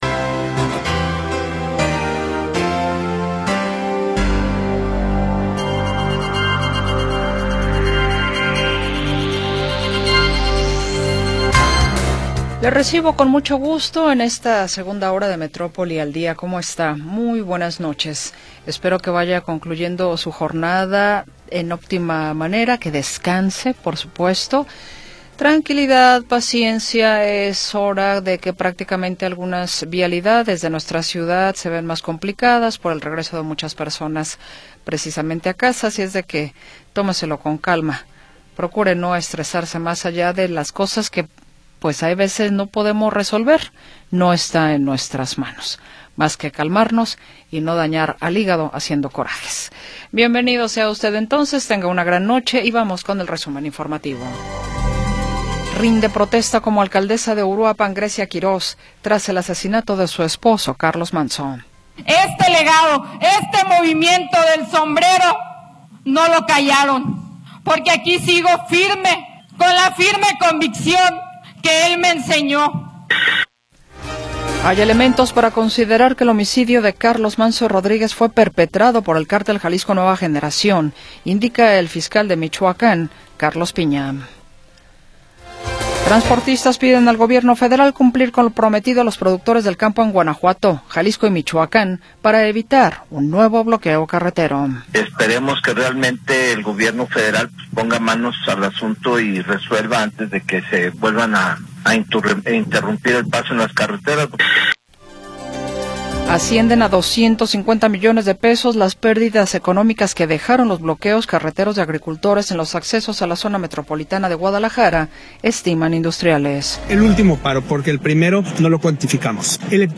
Segunda hora del programa transmitido el 5 de Noviembre de 2025.